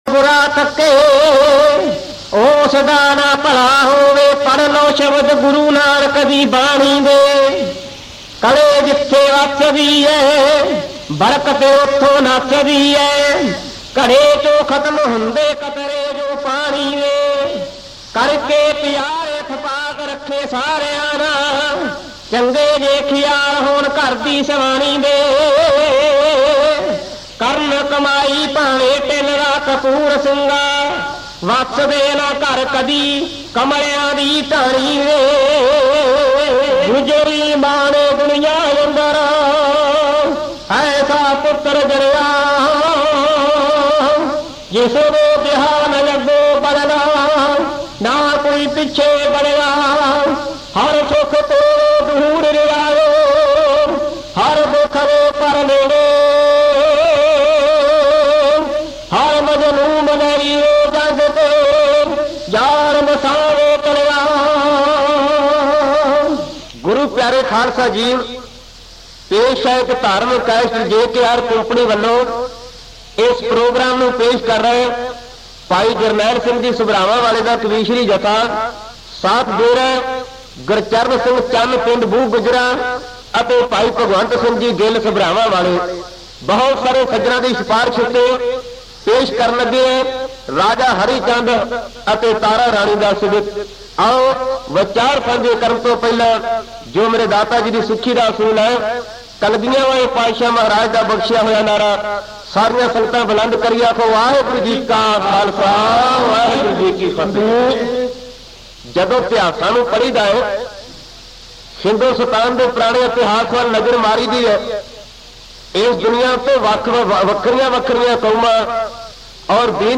Genre: Kavishr